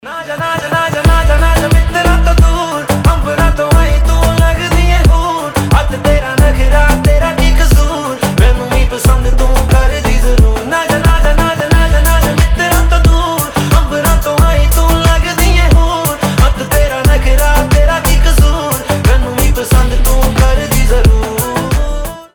• Качество: 320, Stereo
поп
заводные
индийские